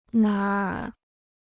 哪儿 (nǎr): où; n'importe où.